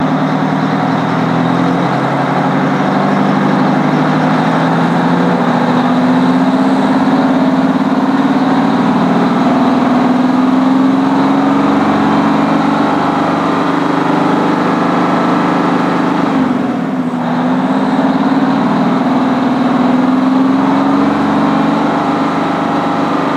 Highwayman Truck Slow Out